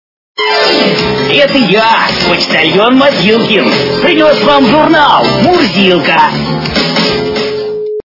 » Звуки » Смешные » Почтальен мобилкин - Это я почтальон Мобилкин. Принес вам журнал Мурзилка
При прослушивании Почтальен мобилкин - Это я почтальон Мобилкин. Принес вам журнал Мурзилка качество понижено и присутствуют гудки.